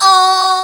choruskid.wav